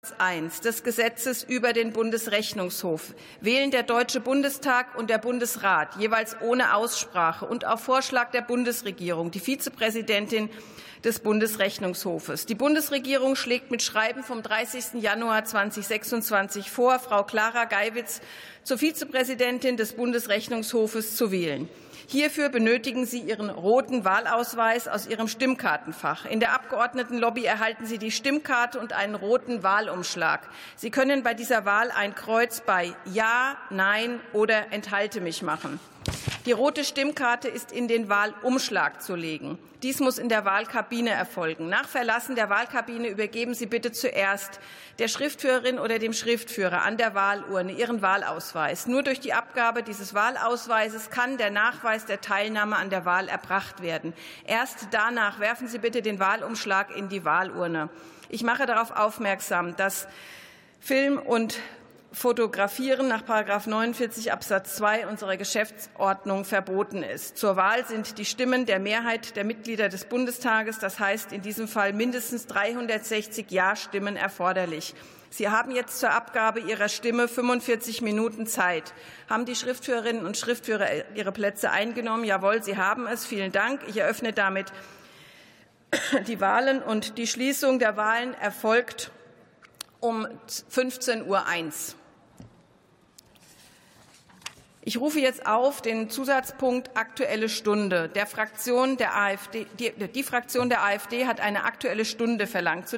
62. Sitzung vom 05.03.2026. TOP 10: Wahl der Vizepräsidentin des Bundesrechnungshofes